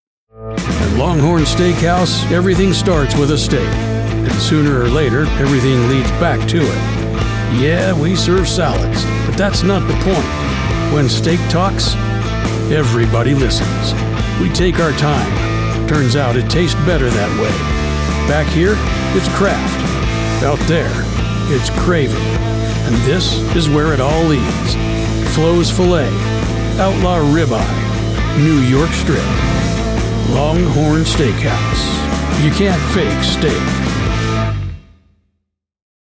• Clean, broadcast‑ready audio from a professional studio
Just a real voice with real grit.
Commercial Voice Over Demos